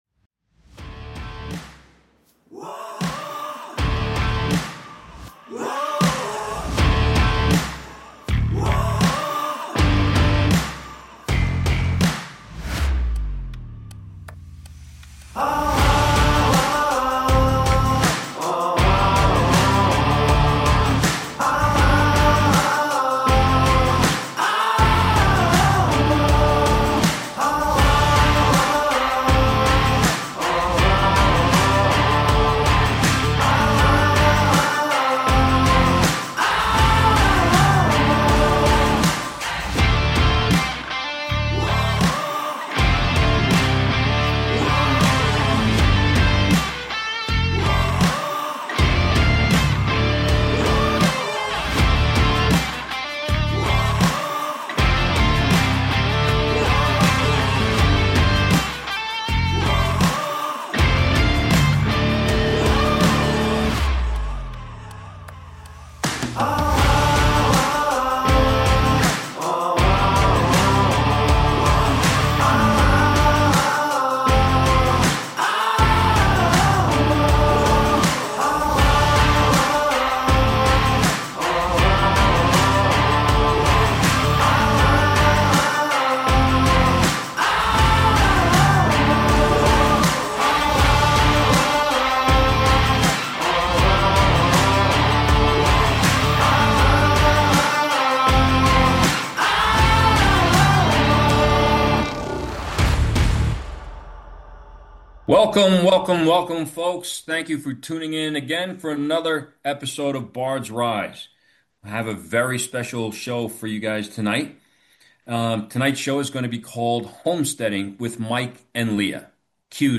Subscribe Talk Show Bards, Rise!